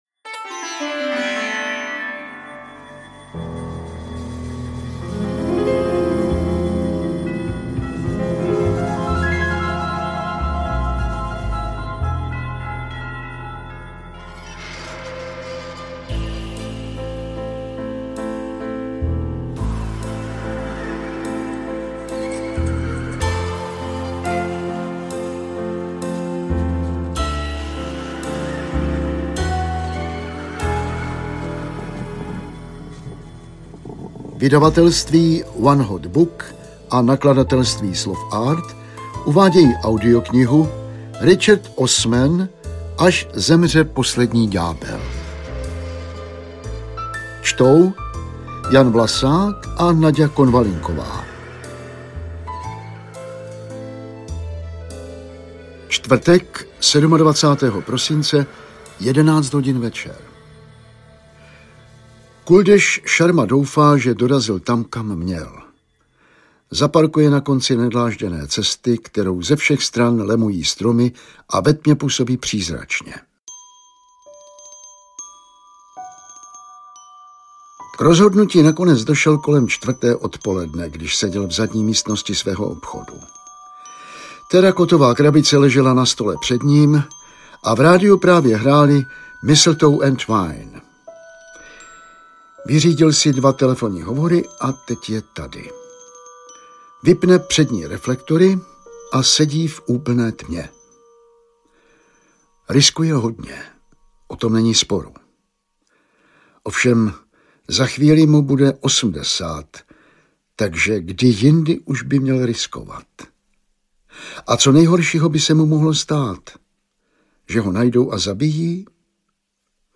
Až zemře poslední ďábel audiokniha
Ukázka z knihy
• InterpretJan Vlasák, Naďa Konvalinková